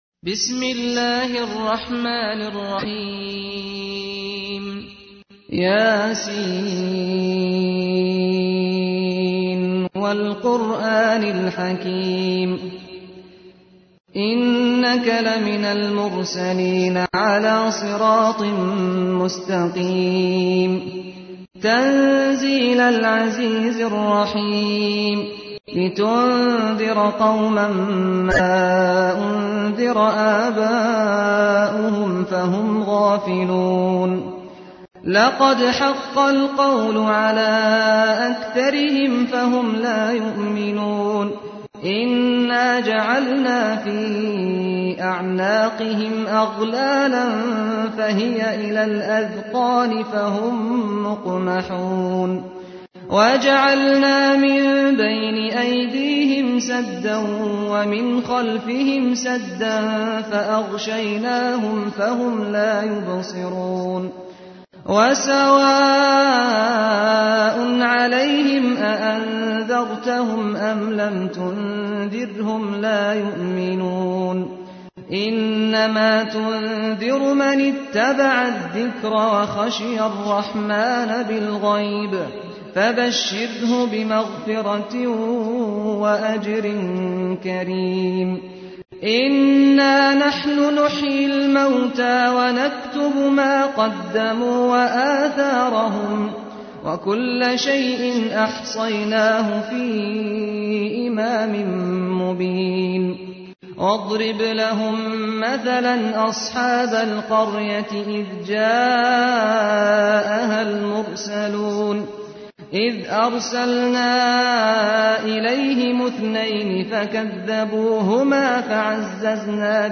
تحميل : 36. سورة يس / القارئ سعد الغامدي / القرآن الكريم / موقع يا حسين